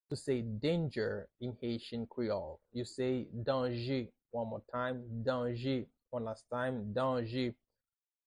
How to say “Danger” in Haitian Creole – “Danje” pronunciation by a native Haitian teacher
“Danje” Pronunciation in Haitian Creole by a native Haitian can be heard in the audio here or in the video below: